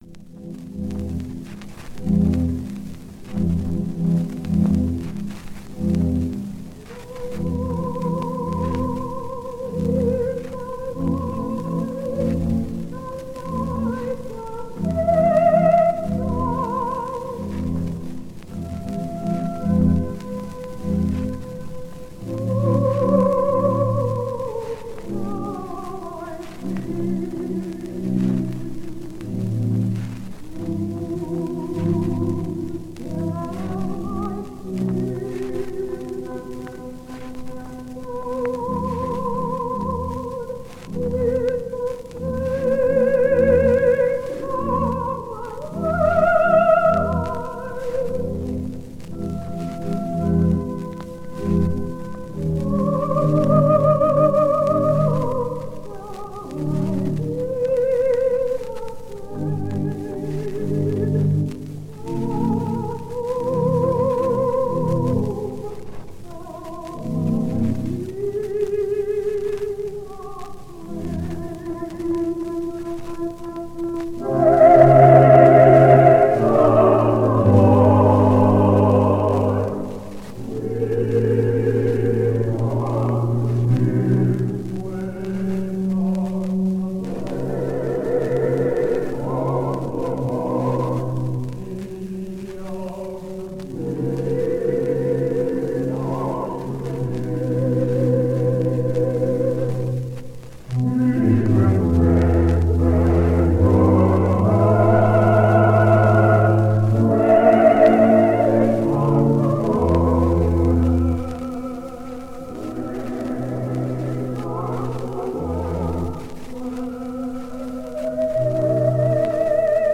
Mom was a soprano.
Solo or with a whole choir singing with her…I could always hear which voice was hers.
The solo female voice is my Mom.